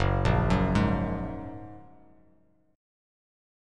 F#add9 chord